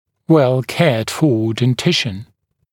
[ˌwel’keədˌfɔː den’tɪʃn][ˌуэл’кеадˌфо: дэн’тишн]зубы, за которыми осуществляется тщательный уход